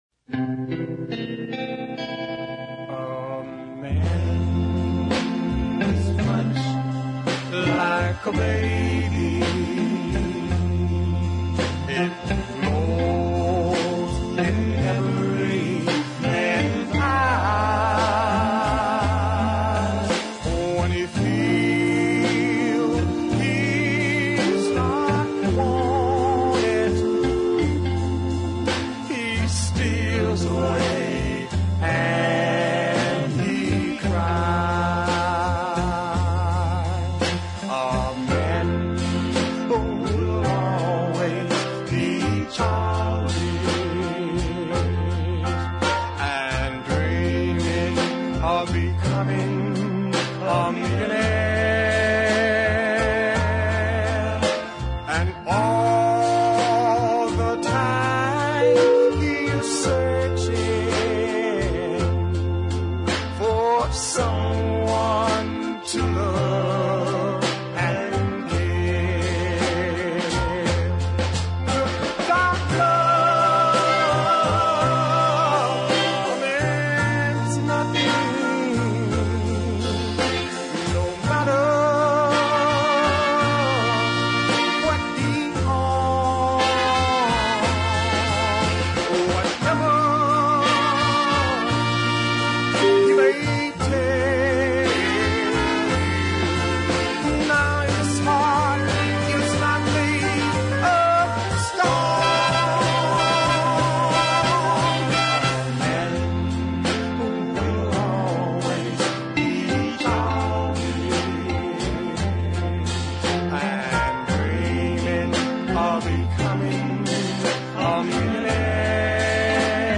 Memphis deep soul
a pleasant light baritone vocal